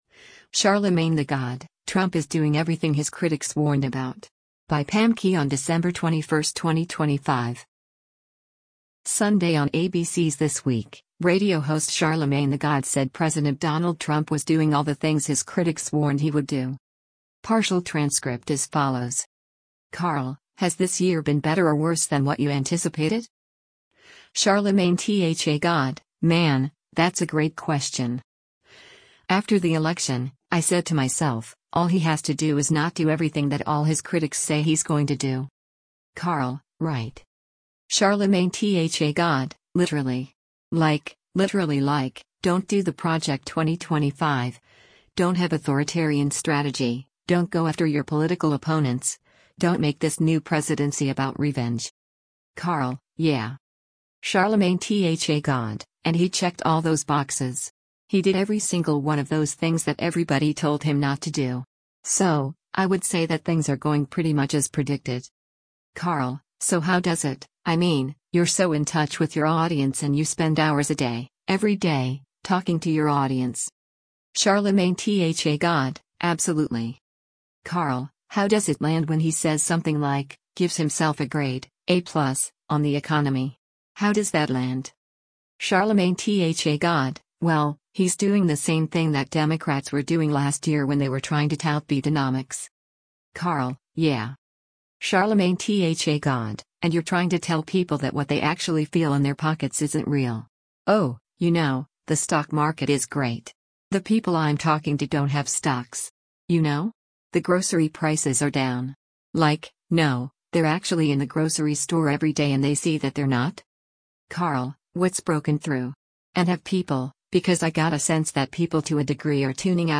Sunday on ABC’s “This Week,” radio host Charlamagne tha God said President Donald Trump was doing all the things his critics warned he would do.